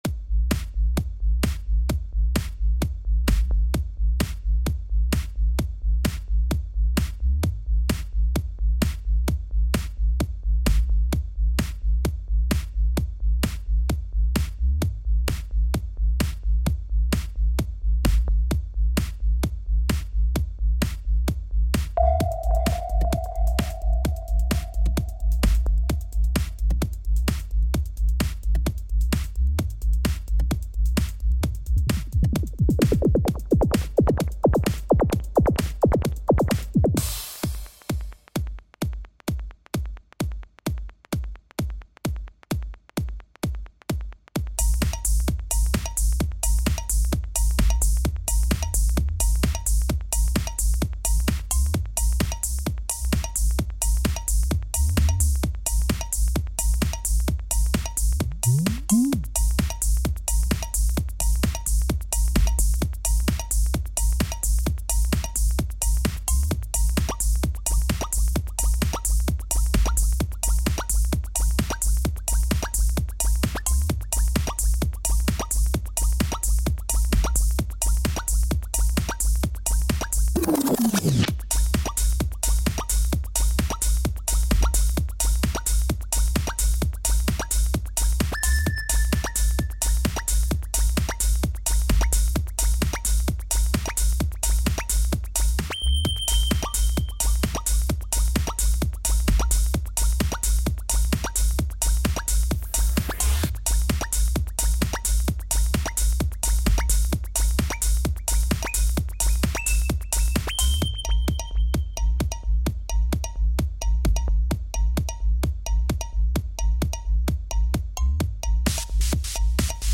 Стиль: Techno